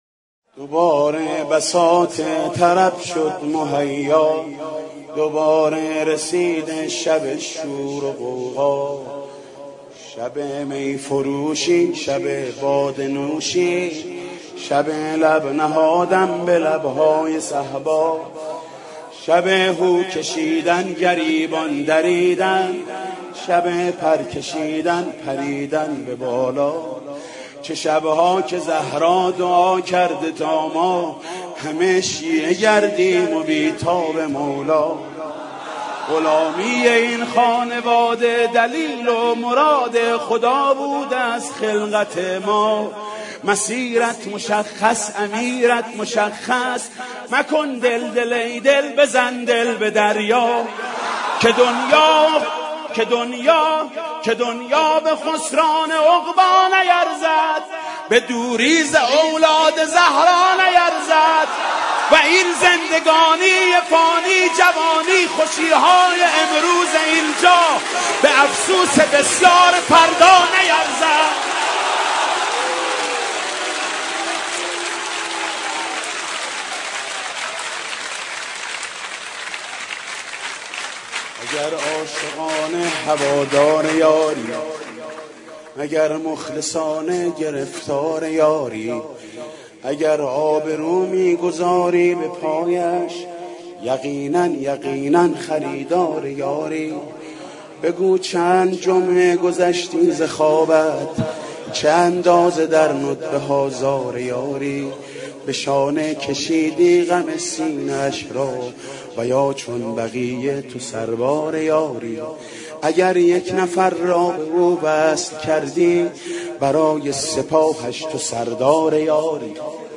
مناجات با امام زمان عجل الله